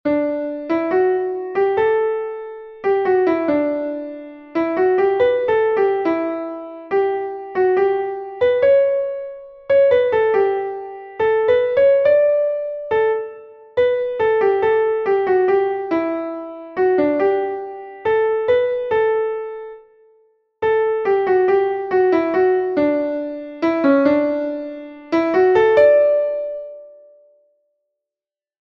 Exercise 2: dotted crotchet and semiquaver  crotchet and semiquaver